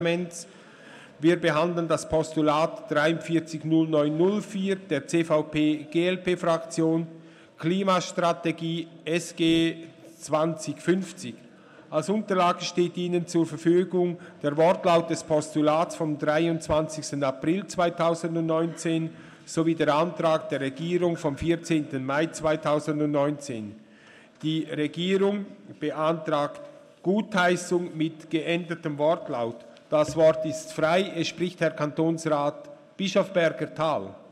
Session des Kantonsrates vom 11. bis 13. Juni 2019